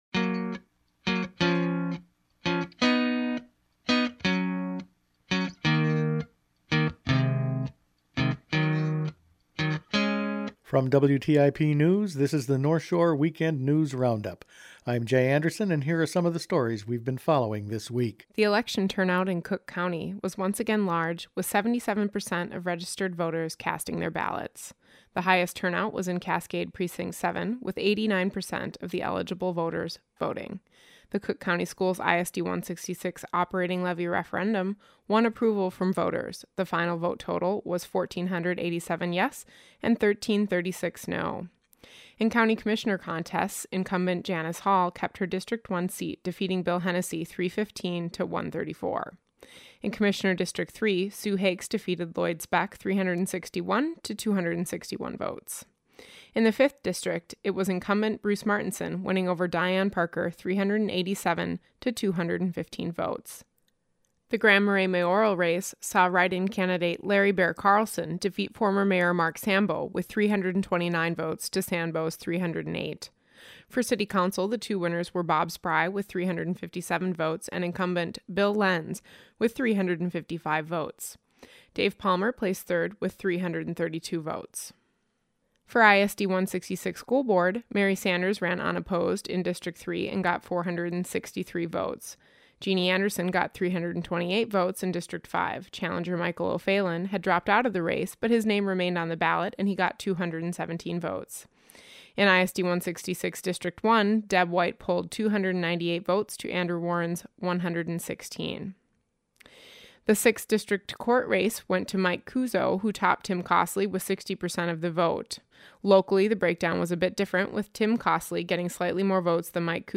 Each weekend WTIP news produces a round up of the news stories they’ve been following this week. Final election results for Cook County was the top story this week.